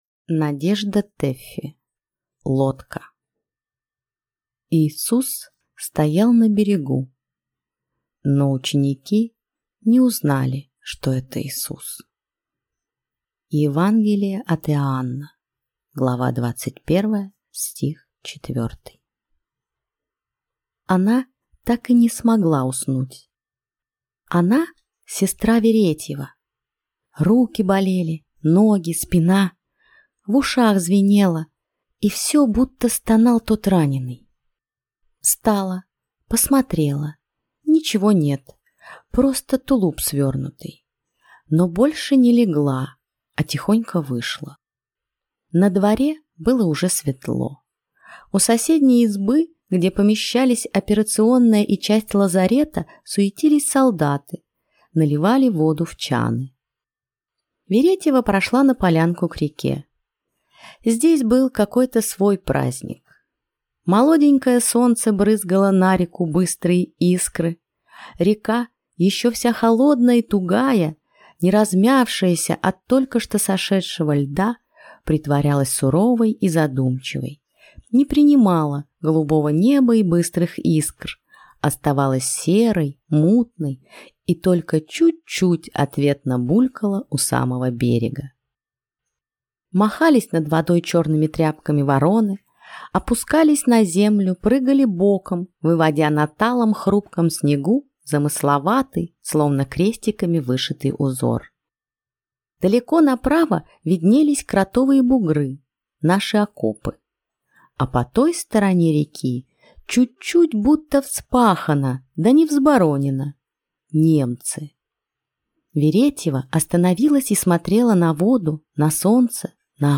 Аудиокнига Лодка | Библиотека аудиокниг